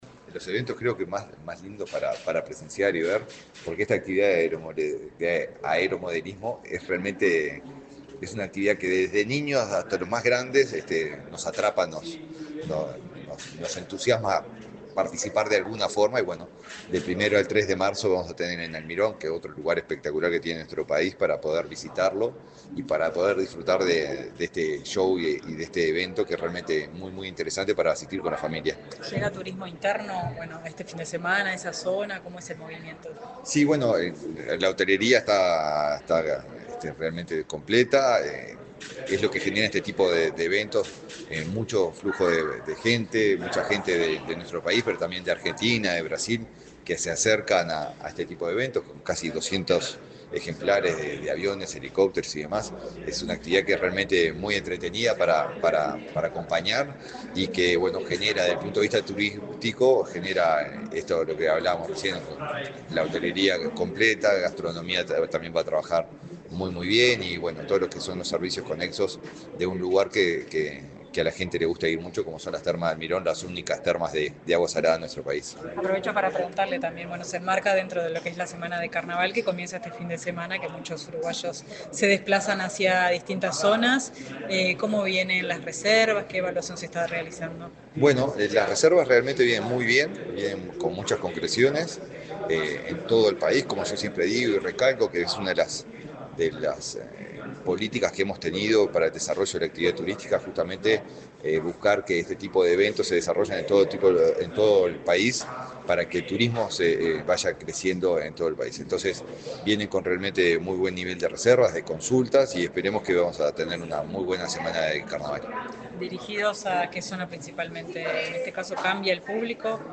Declaraciones del ministro de Turismo, Eduardo Sanguinetti
Declaraciones del ministro de Turismo, Eduardo Sanguinetti 25/02/2025 Compartir Facebook X Copiar enlace WhatsApp LinkedIn El ministro de Turismo, Eduardo Sanguinetti, participó en el lanzamiento del 8.º Festival Vuela Termas, una actividad de aeromodelismo, que se desarrollará entre el 1.° y el 3 de marzo en Almirón, departamento de Paysandú. Luego dialogó con la prensa.